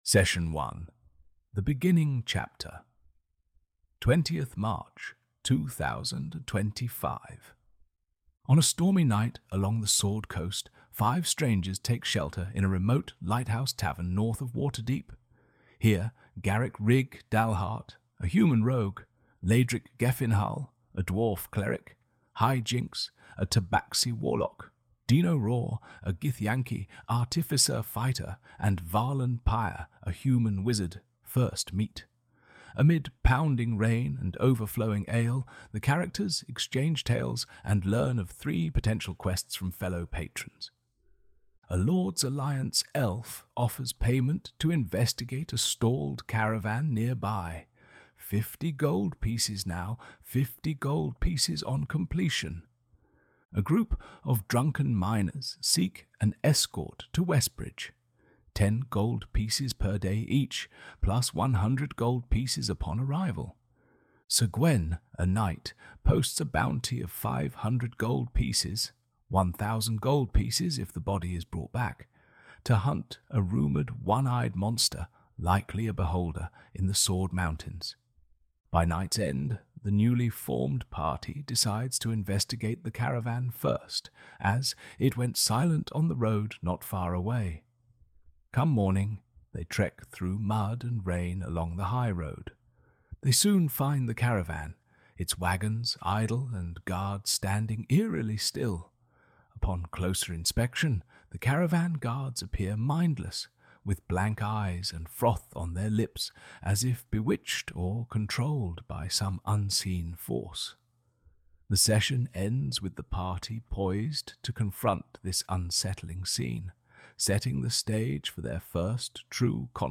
Bard Lyrics "The Beginning Chapter" (A Bardic Ballad for Lute) [Verse 1] On a stormy night b...